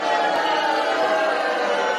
Free SFX sound effect: Punch Impact.
Punch Impact
350_punch_impact.mp3